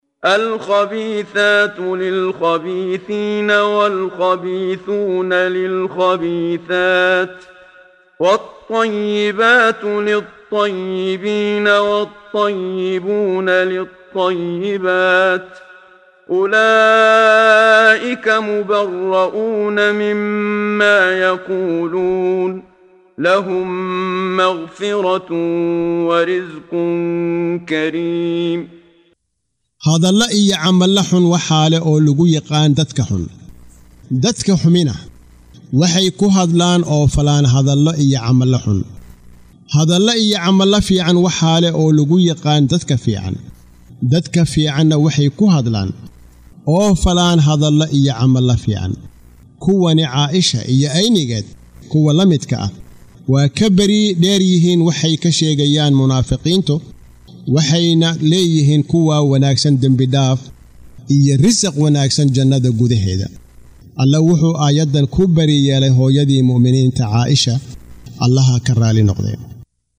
Waa Akhrin Codeed Af Soomaali ah ee Macaanida Suuradda An-Nuur ( Nuurka ) oo u kala Qaybsan Aayado ahaan ayna la Socoto Akhrinta Qaariga Sheekh Muxammad Siddiiq Al-Manshaawi.